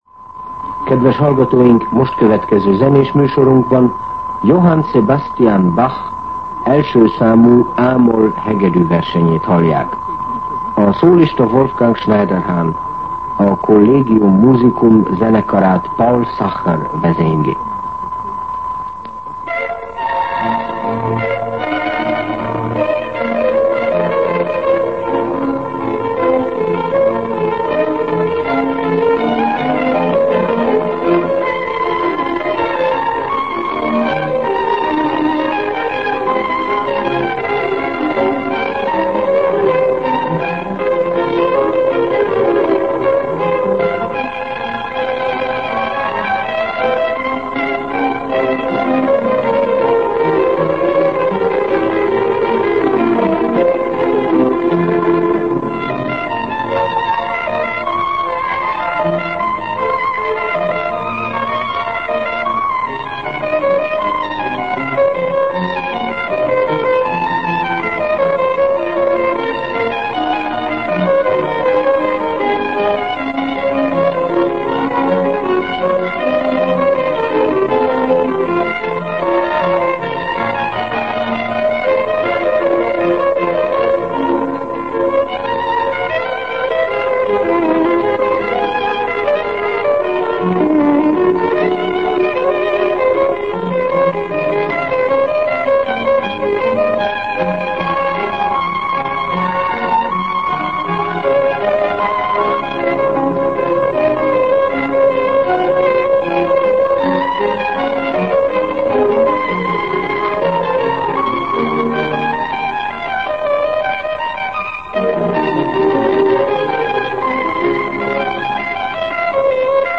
Zene
Most következő zenés műsorunkban Johann Sebastian Bach első számú, A-moll hegedűversenyét hallják. A szólista Wolfgang Schneiderhan. A Collegium Musicum zenekarát Paul Sacher vezényli. zene Információk Adásba került 1956-11-03 7:11 Hossz 0:04:44 Cím Zene Megjegyzés csonka Műsor letöltése MP3